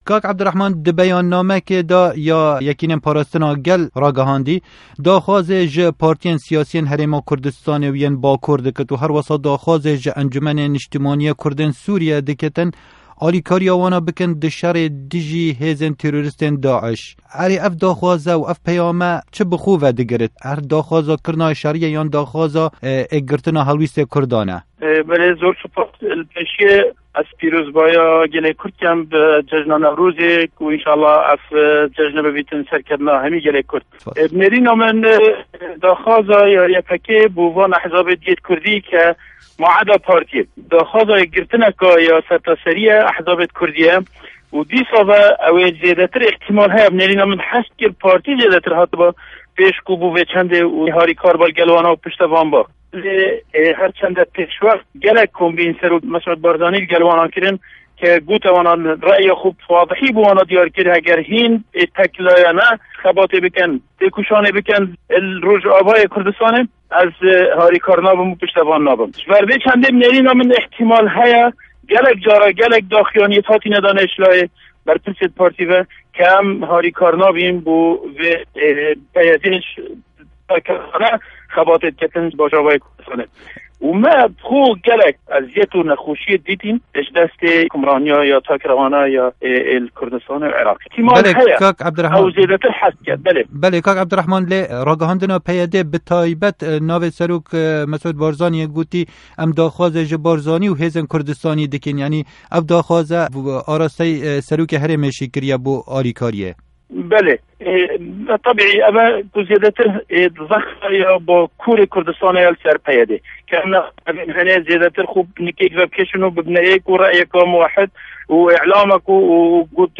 Hevpeyvîn bi Ebdulrahman Zaxoy re